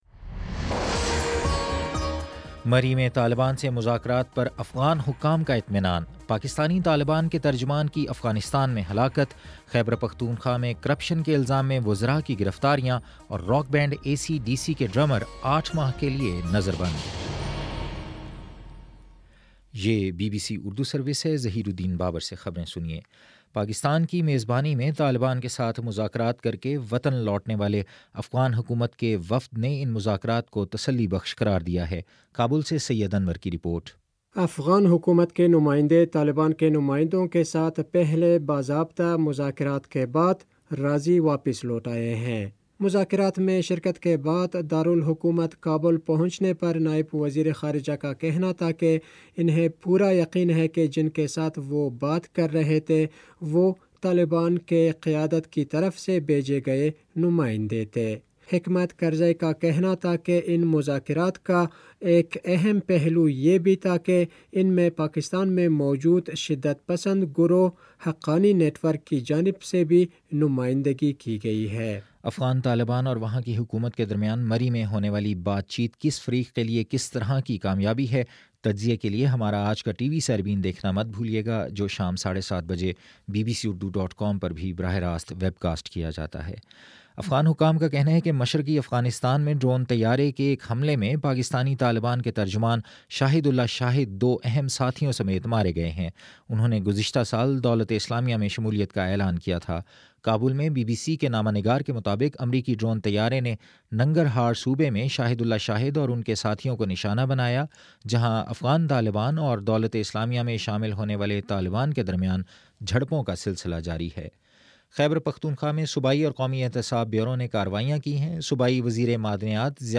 جولائی 9: شام سات بجے کا نیوز بُلیٹن